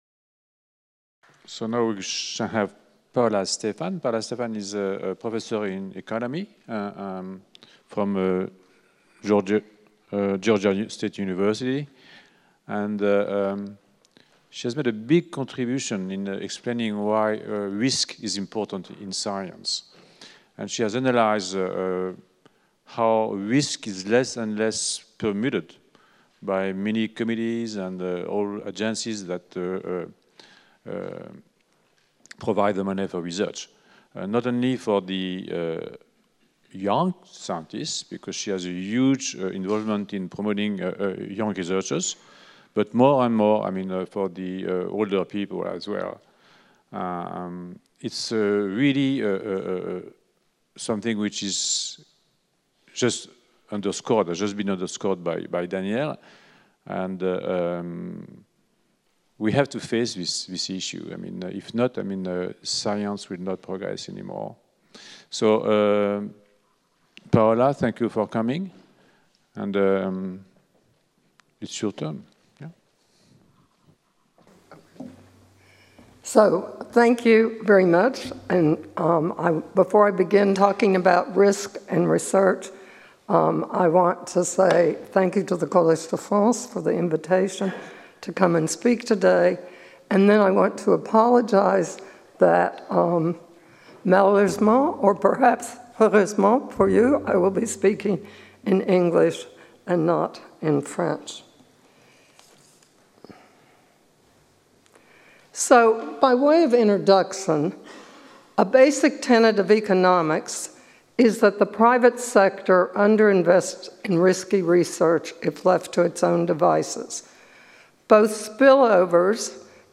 Audio recording (Simultaneous translation)